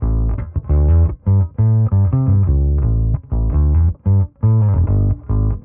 Index of /musicradar/dusty-funk-samples/Bass/85bpm
DF_PegBass_85-A.wav